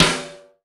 SNARE 019.wav